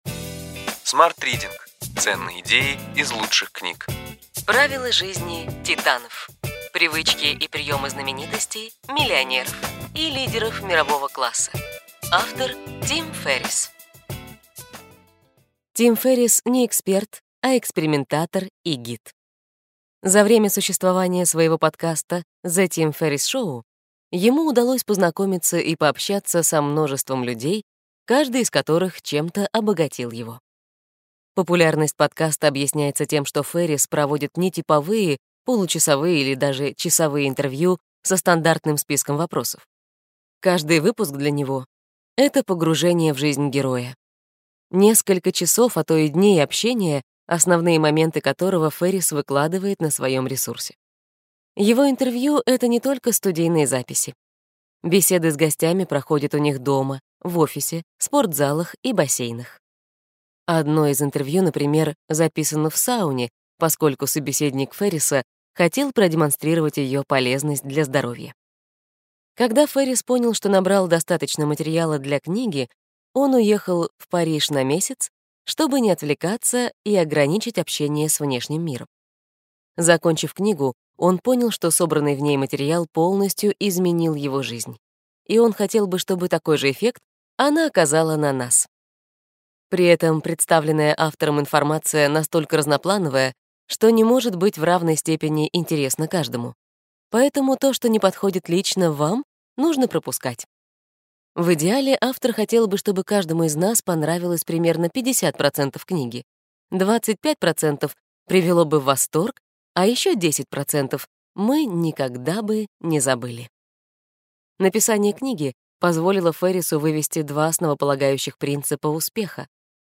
Аудиокнига Ключевые идеи книги: Правила жизни Титанов: привычки и приемы знаменитостей, миллионеров и лидеров мирового класса.